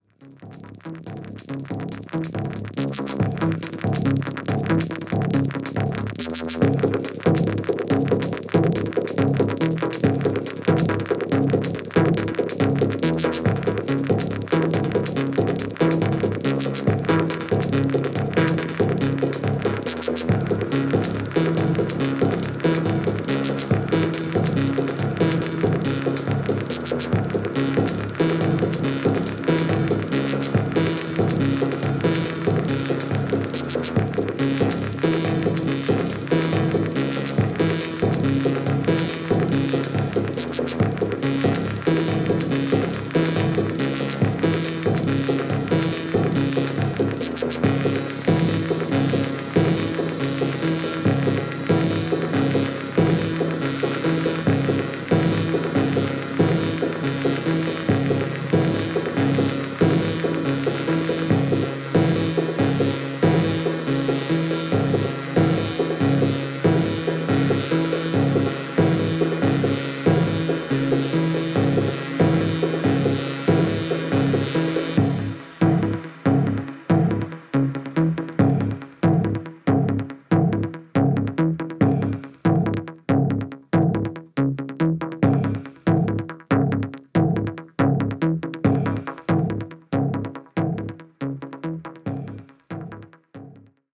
2006年ライブ録音でこの世界観！！！圧巻です！！！
Synthesizer, Effects
Guitar, Effects